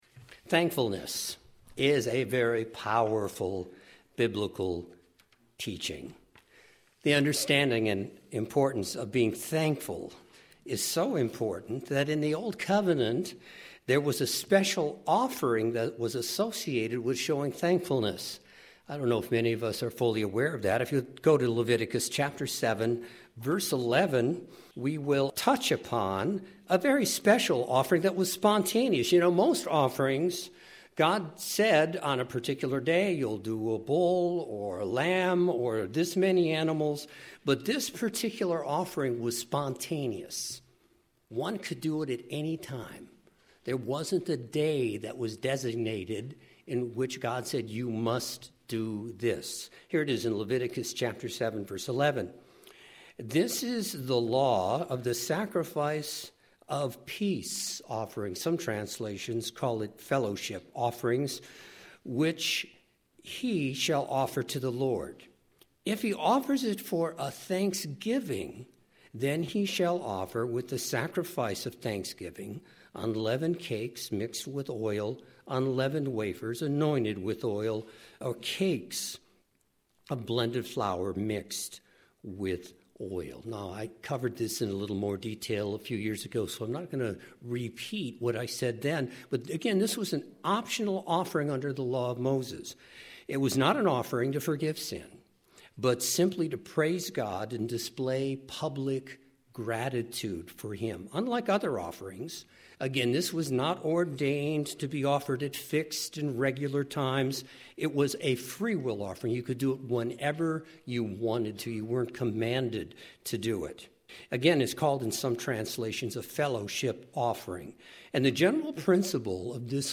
An attitude of thankfulness is a Godly quality, and we as God’s people have more to be thankful for than anyone on else earth! Today in this Sermon, I would like to give 7 reasons we have to be “thankful” in this lifetime.